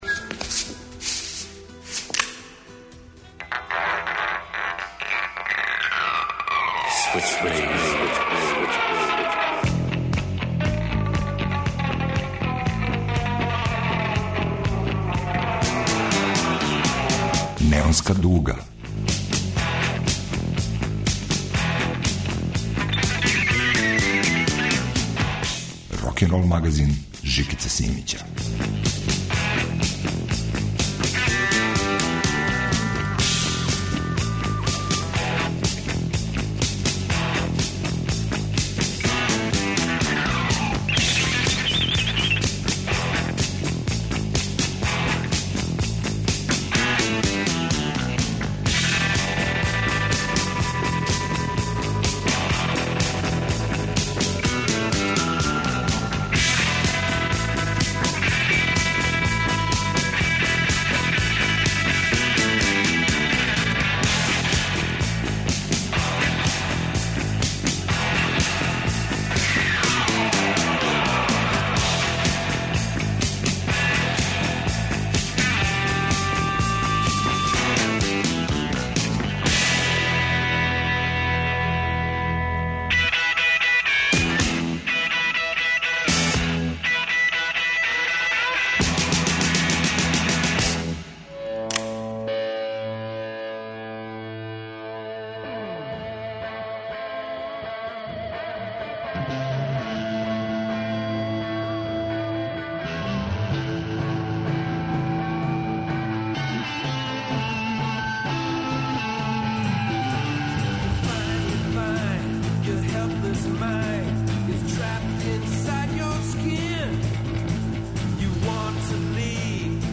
Реверберације и контемплације. Једна немогућа плејлиста. Џез, фолк и психоделични рок.